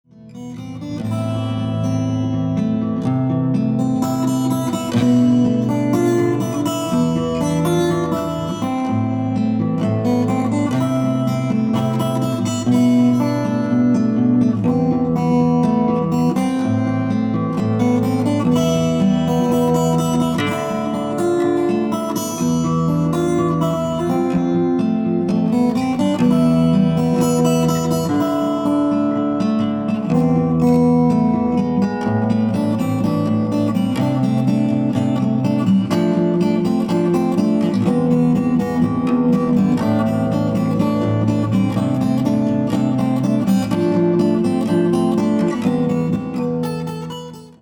• Качество: 320, Stereo
гитара
красивые
без слов
Cover
инструментальные
романтичные
Красивый инструментальный cover